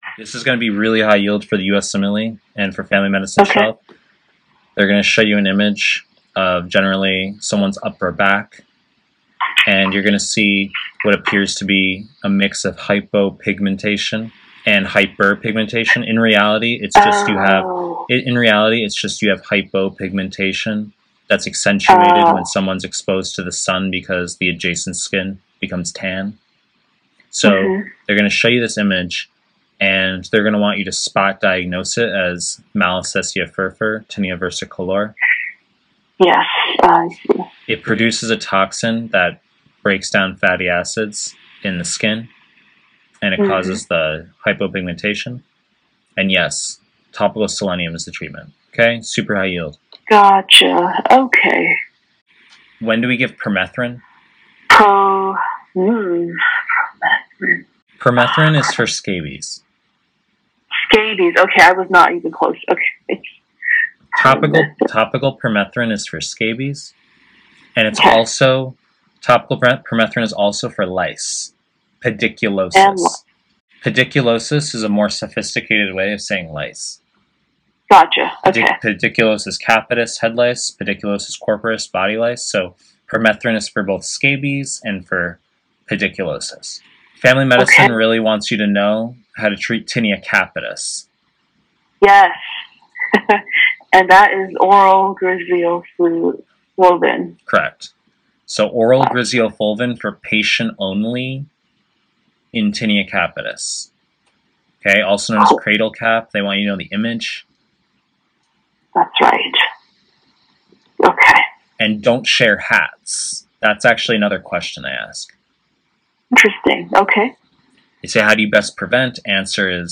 Pre-recorded lectures